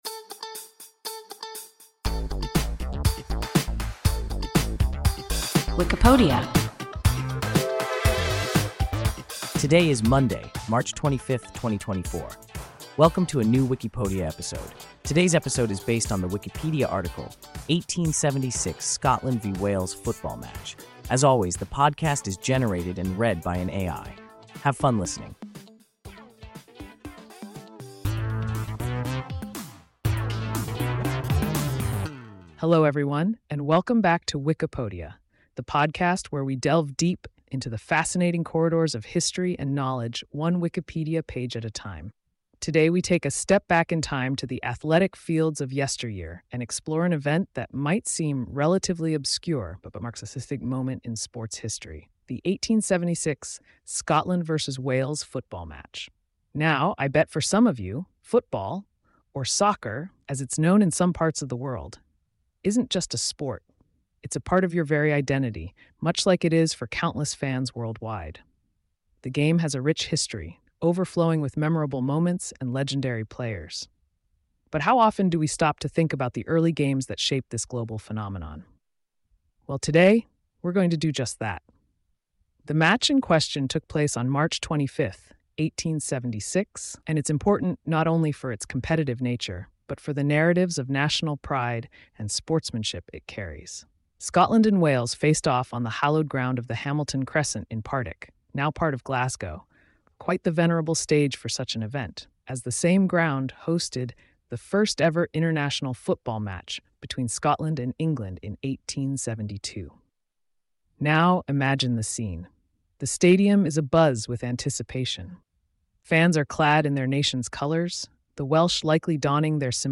1876 Scotland v Wales football match – WIKIPODIA – ein KI Podcast